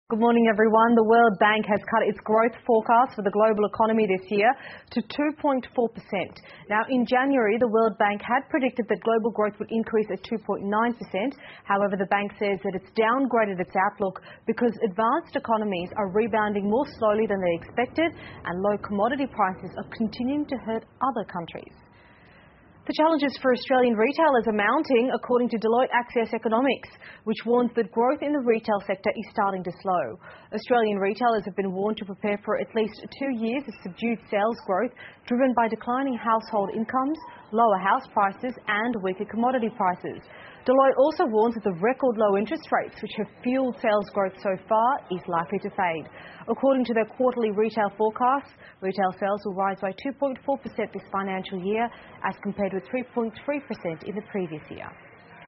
澳洲新闻 (ABC新闻快递) 世行下调全球经济增长率至2.4% 听力文件下载—在线英语听力室